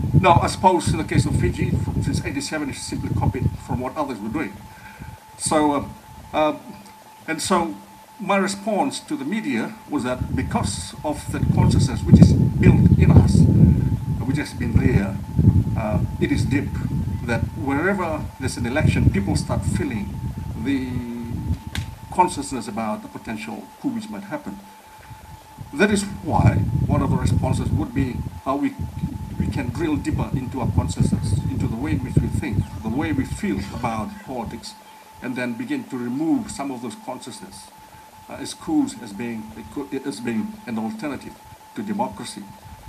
This was highlighted by renowned political sociologist Professor Steven Ratuva during the National Federation Party’s 60th anniversary over the weekend.